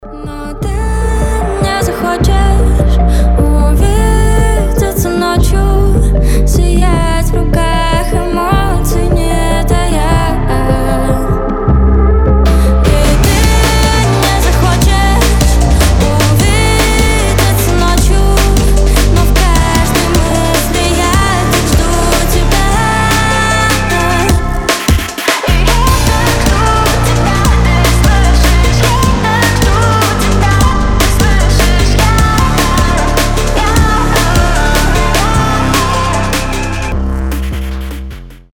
• Качество: 320, Stereo
громкие
женский голос
нарастающие
драм энд бейс